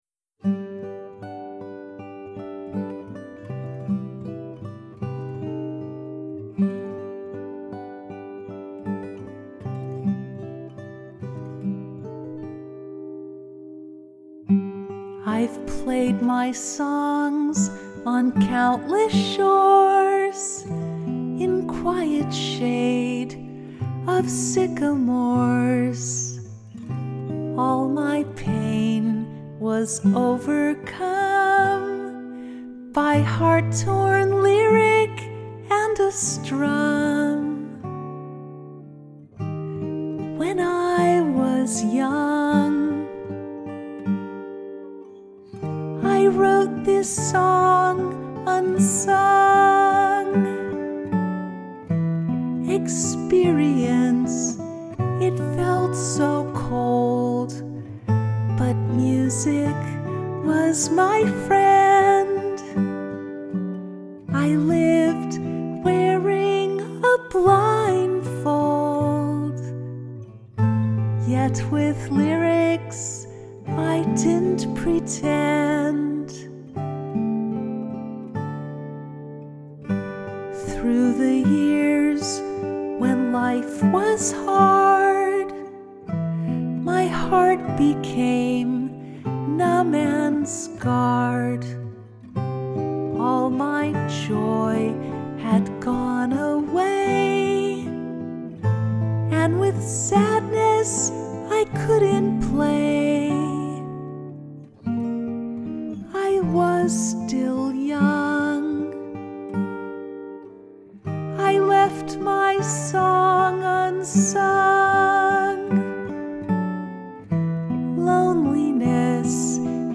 My Song Unsung Acoustic 2018
my-song-unsung-acoustic-5-7-18.mp3